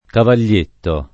[ kaval’l’ % tto ]